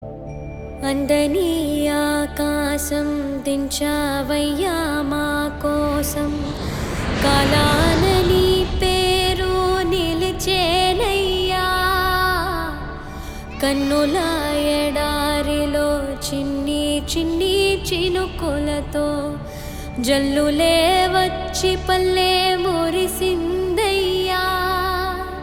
best flute ringtone download | love song ringtone